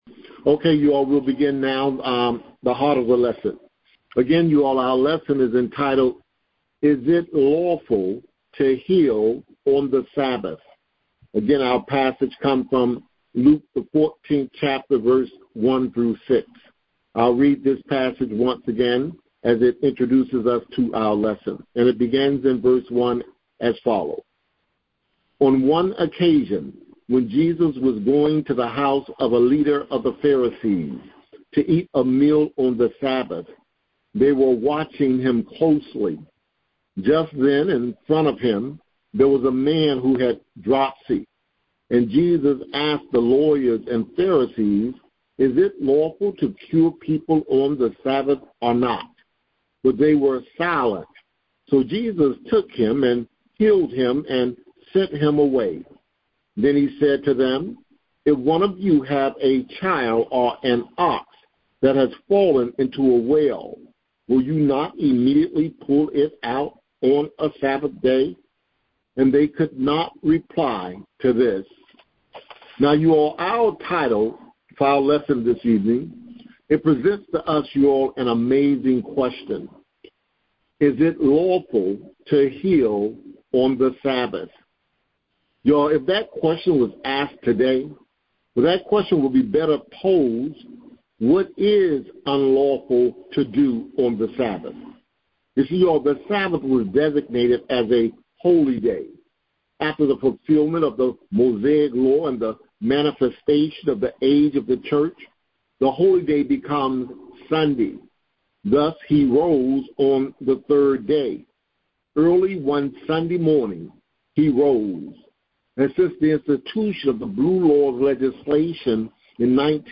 07 Sunday School Lessons – Is it lawful to heal on the Sabbath | Faith Driven Grace Living Ministries